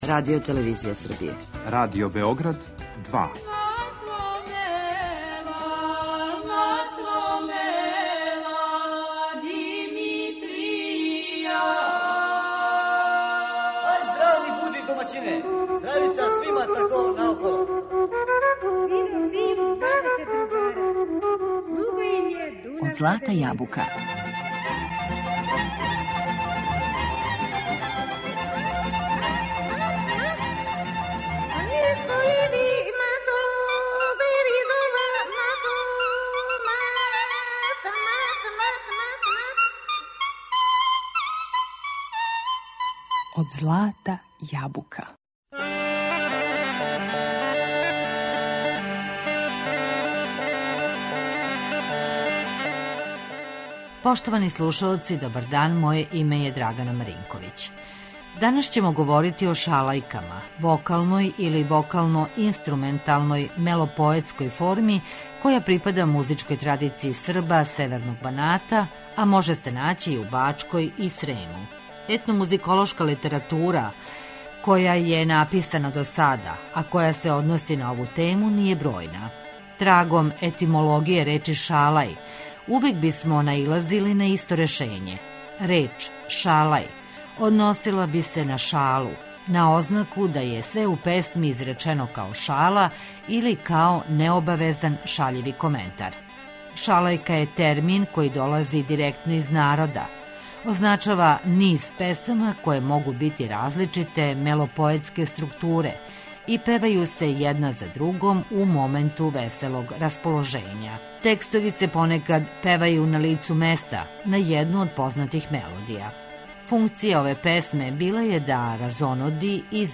Данас ћемо говорити о шалајкама, вокалној или вокално-инструменталној мелопоетској форми која припада традицији Срба северног Баната, а може се наћи и у Бачкој и Срему.